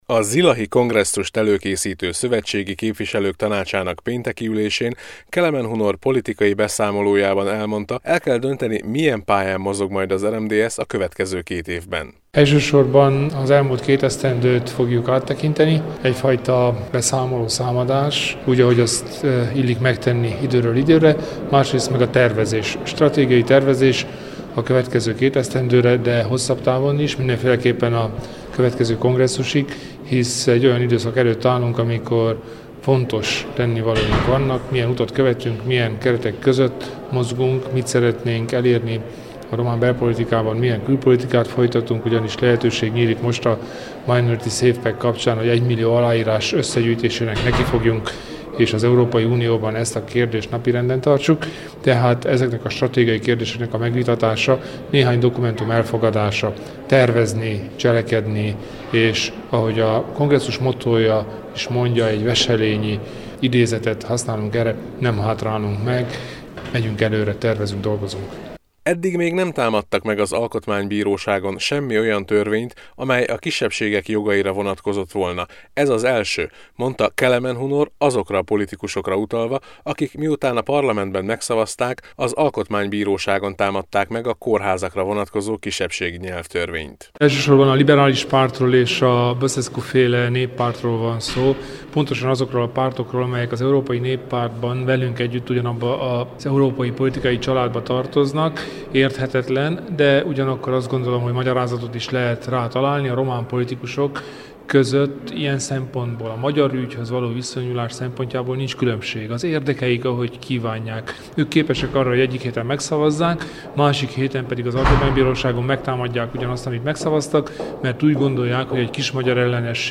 A Szövetségi Képviselők Tanácsa ülésének legfontosabb feladata, hogy előkészítse az RMDSZ zilahi kongresszusát – emelte ki Kelemen Hunor mai politikai tájékoztatójában.